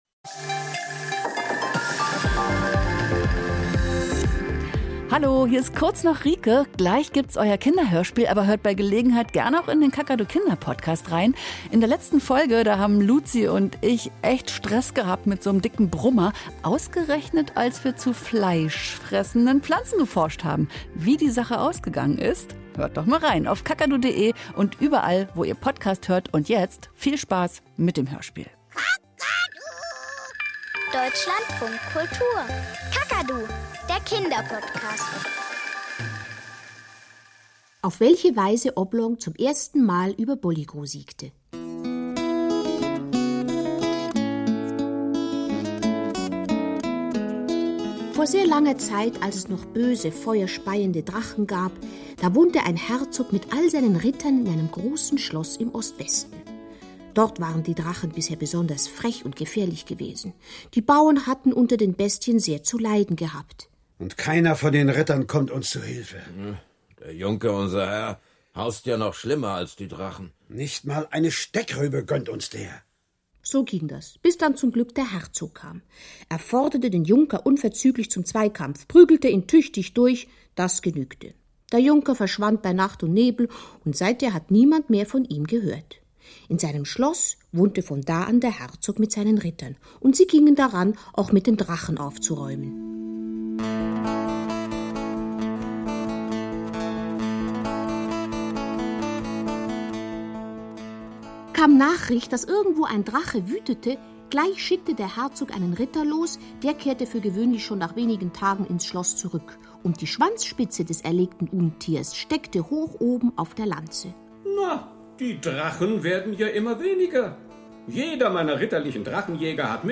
Der kleine dicke Ritter - Ein Kinderhörspiel in sechs Teilen